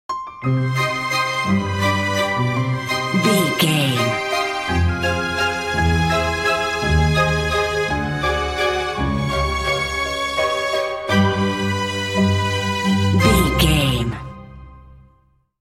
A dark orchestral track with suspicious undertones.
Aeolian/Minor
dark
melancholic
eerie
suspense
tension
cello
double bass
violin
trumpet
orchestra
harp
piano
flutes
oboe